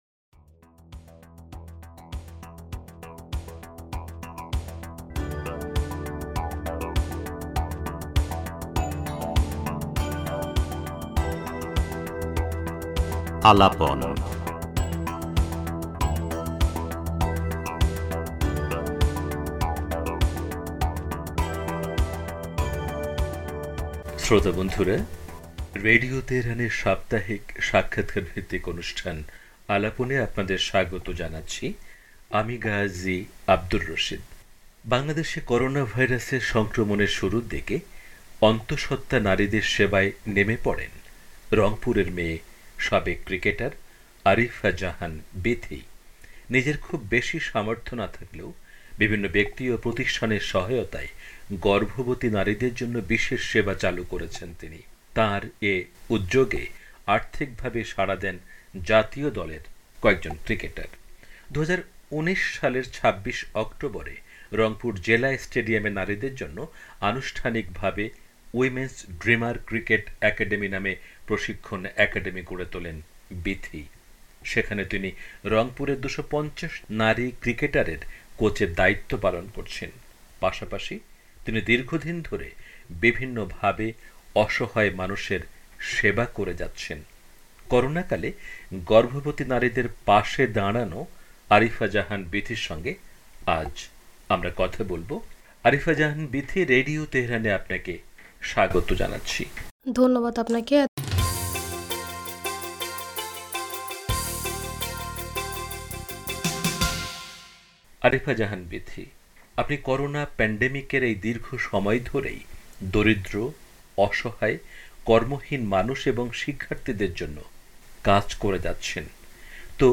সাক্ষাৎকার